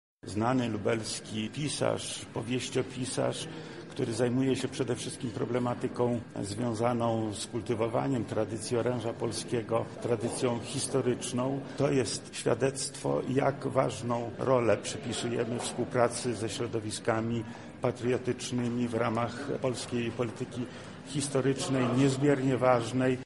O tym, za jakie działania został doceniony, mówi Wojewoda Lubelski Lech Sprawka: